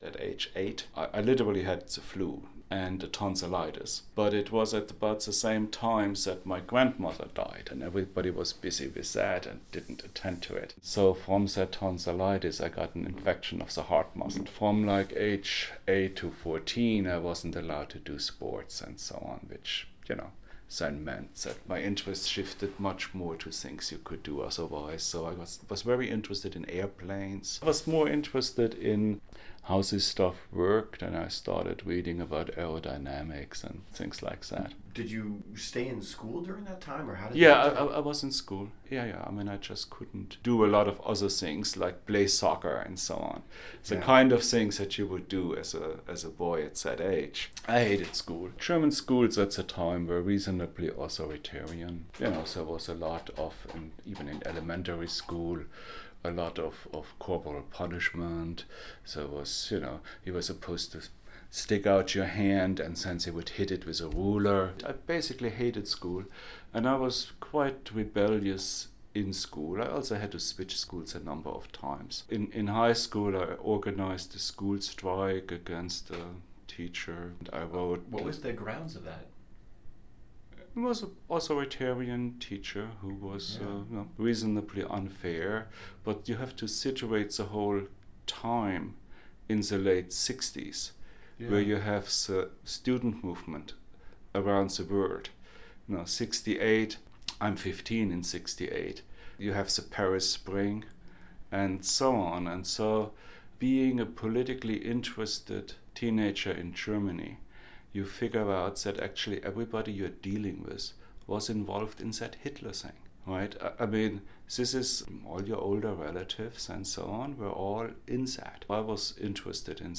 In this next excerpt he describes his experience in school in a manner that dramatically sketches the rise of his indomitable intellect and independence. He recalled the heart condition that cast a shadow over his activities from years 8-14, his involvement with protests and rebellion against the authoritarian approach of his school, and eventually leaving home at 16 to live/work independently and complete his early education before heading to university.